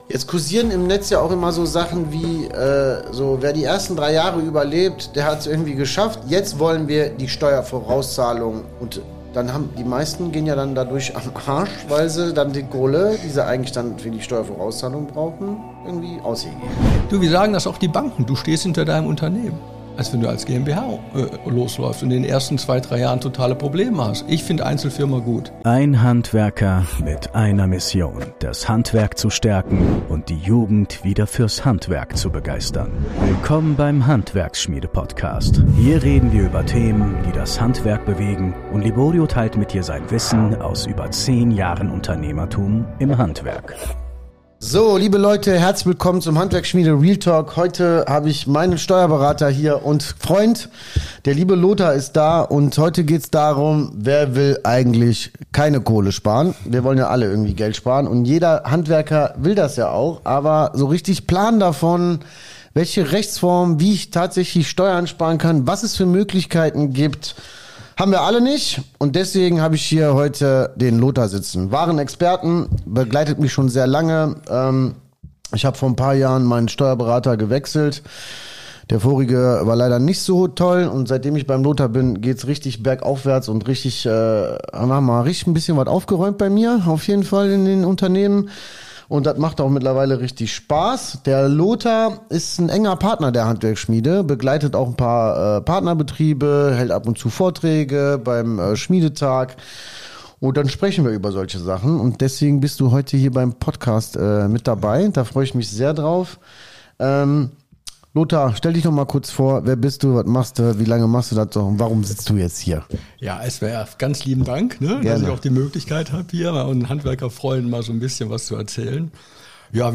So bleibt die Kohle auf deinem Konto - und landet nicht beim Finanzamt | Interview